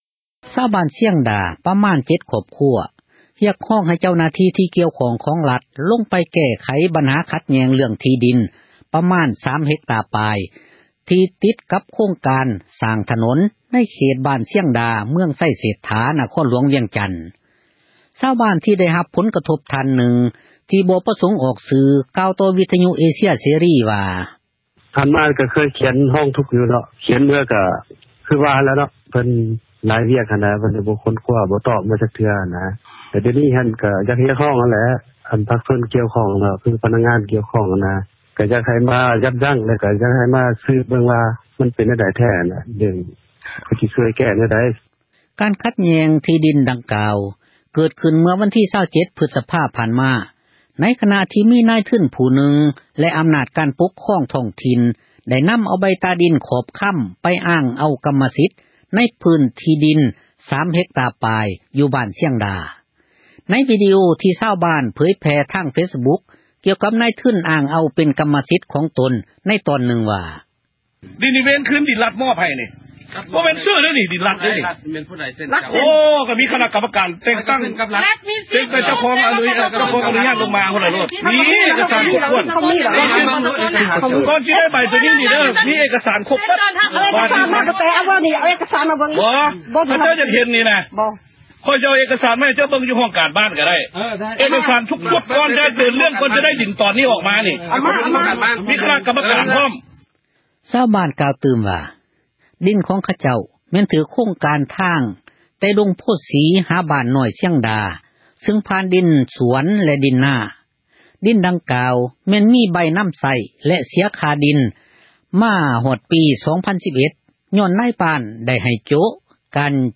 ຊາວບ້ານ ທີ່ ໄດ້ຮັບ ຜົນ ກະທົບ ທ່ານນຶ່ງ ທີ່ ບໍ່ ປະສົງ ອອກຊື່ ກ່າວຕໍ່ ວິທຍຸ ເອເຊັຽ ເສຣີ ວ່າ: